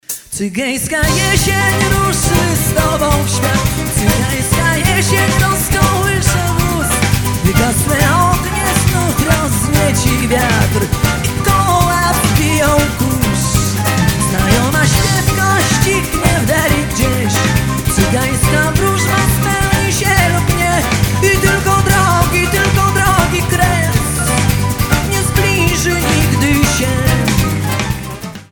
chórki
gitara
gitara basowa
gitara akustyczna
instr. klawiszowe
instr. perkusyjne
sekcja smyczkowa